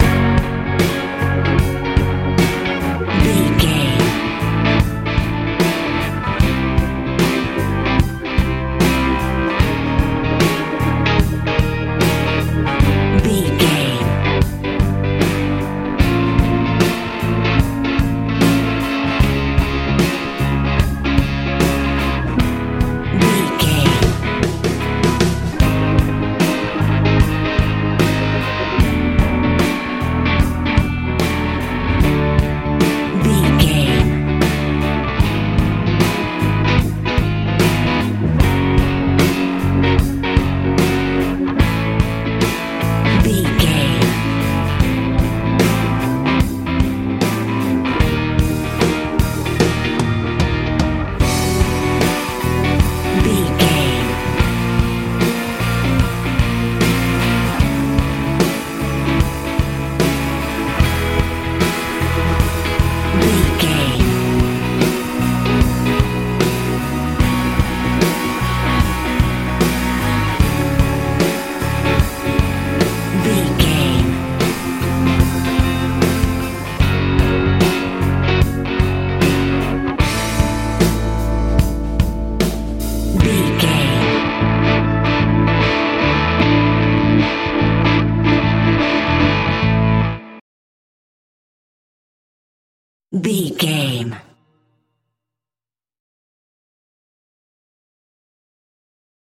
pop country rock feel
Ionian/Major
B♭
inspirational
light
organ
electric guitar
bass guitar
drums
90s
funky
bright